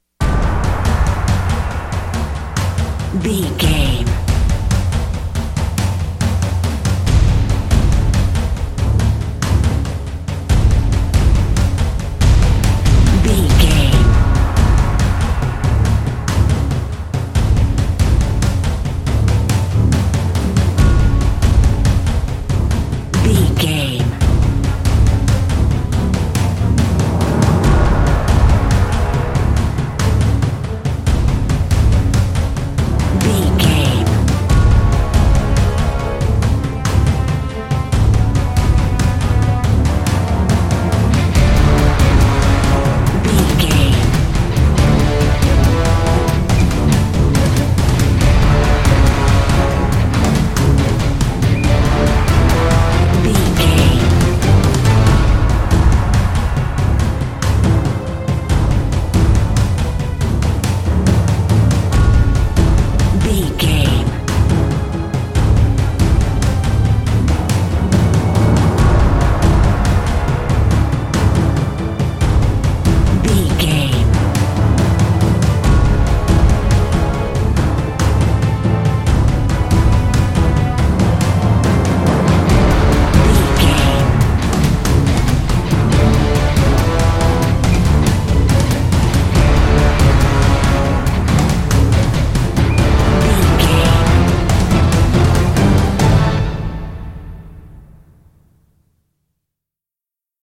Epic / Action
Fast paced
In-crescendo
Aeolian/Minor
strings
horns
percussion
electric guitar
orchestral hybrid
dubstep
aggressive
energetic
intense
synth effects
driving drum beat